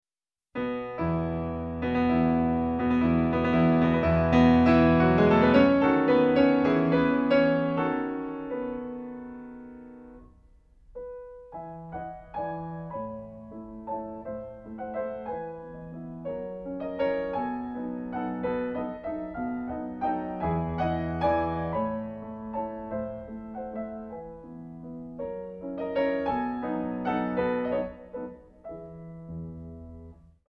Pianist
refined, patrician touch
E major, Op. Posth.